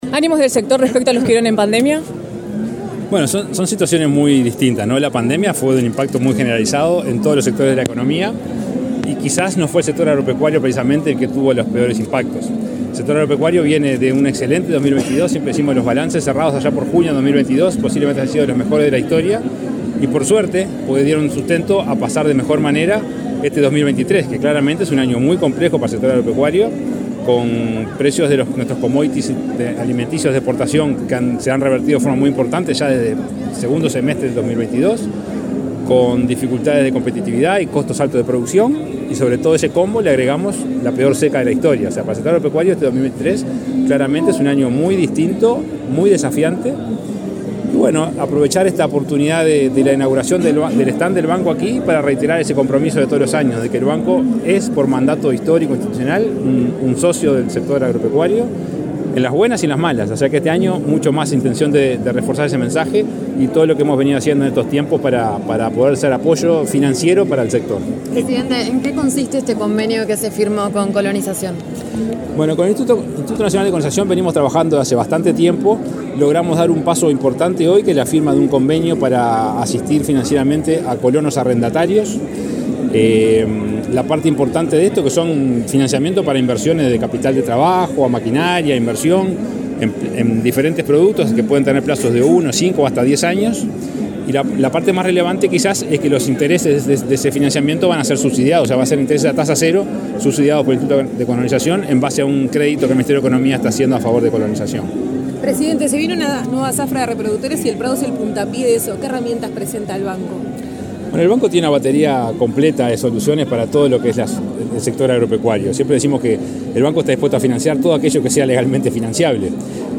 Declaraciones del presidente del BROU, Salvador Ferrer
Declaraciones del presidente del BROU, Salvador Ferrer 12/09/2023 Compartir Facebook X Copiar enlace WhatsApp LinkedIn El presidente del Banco de la República Oriental del Uruguay (BROU), Salvador Ferrer, dialogó con la prensa en la Expo Prado, luego de firmar un acuerdo con el Instituto Nacional de Colonización para otorgar asistencia financiera a familias colonas.